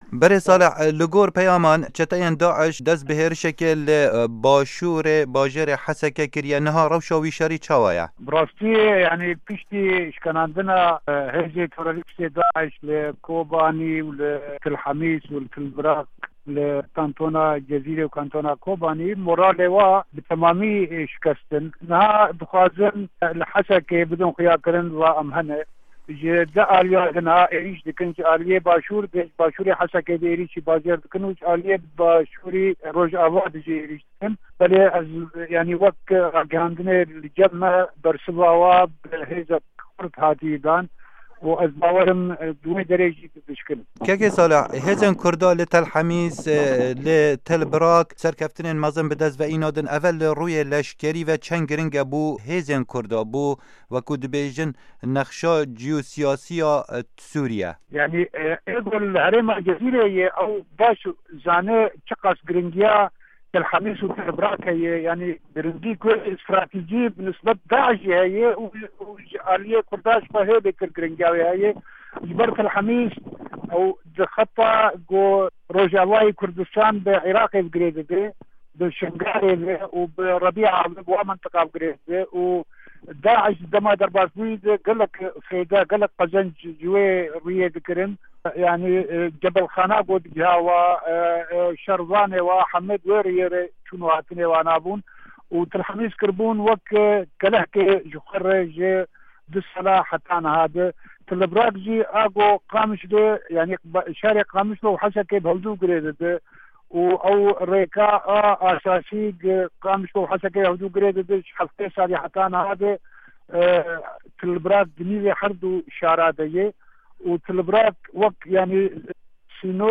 Berpirsê têkeliyên derve li Kantona Cizirê Salih Gedo di hevpeyvînekê de ligel Dengê Amerîka dibêje, Kurd li Rojava û Başurê Kurdistanê xwedî bandora her sereke ne di şerê dijî terorê de.
Hevpeyvin digel Salih Gedo